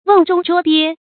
注音：ㄨㄥˋ ㄓㄨㄙ ㄓㄨㄛ ㄅㄧㄝ
甕中捉鱉的讀法